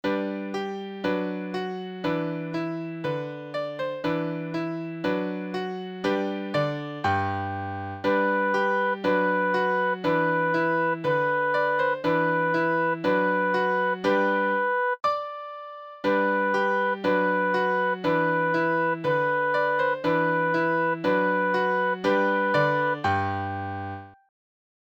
リコーダー練習
３年生のリコーダー練習用のファイルを作成しました。